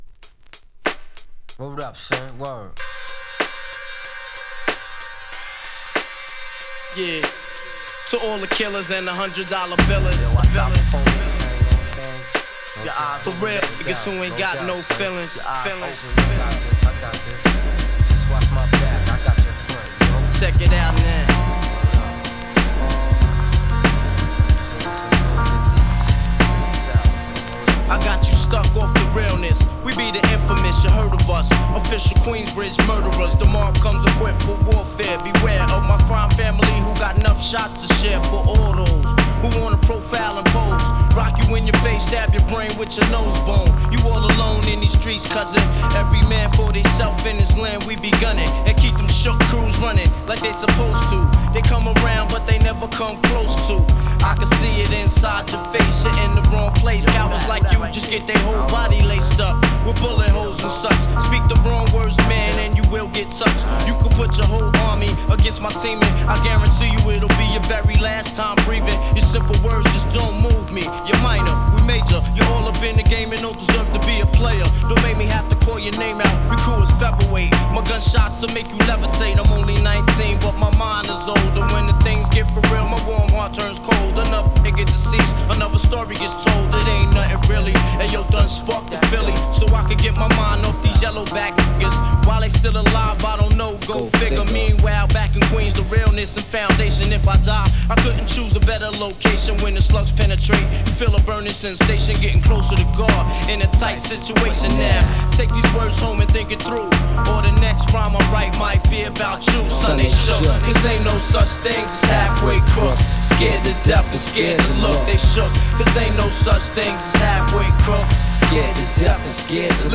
US Original press !90's HIP HOP クラシック中のクラシック！